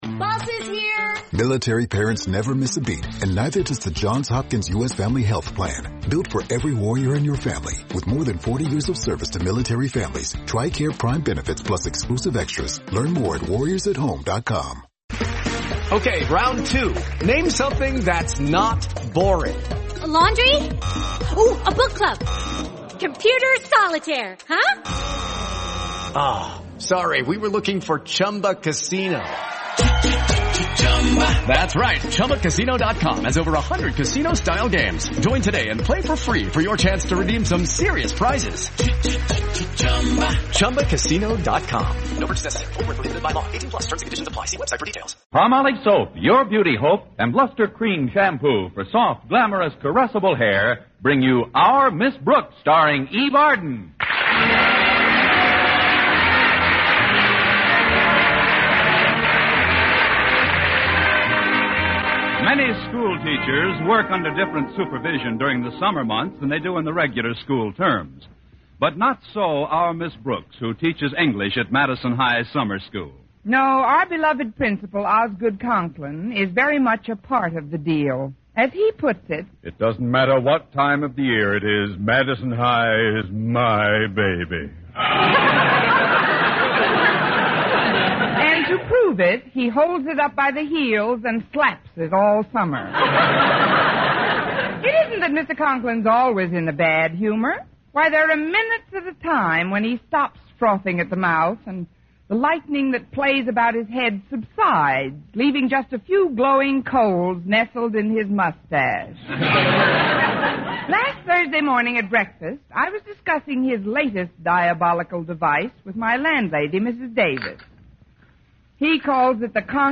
Our Miss Brooks was a beloved American sitcom that ran on CBS radio from 1948 to 1957.
The show starred the iconic Eve Arden as Connie Brooks, a wisecracking and sarcastic English teacher at Madison High School.
Gale Gordon played the uptight and pompous Principal Osgood Conklin, Richard Crenna played the dimwitted but lovable student Walter Denton, and Jane Morgan played Miss Brooks' scatterbrained landlady, Mrs. Davis.